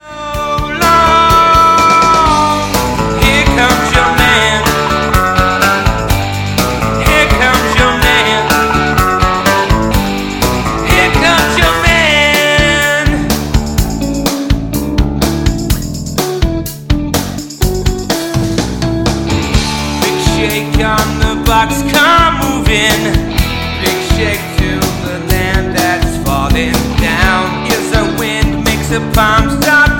Backing track files: 1980s (763)
Buy Without Backing Vocals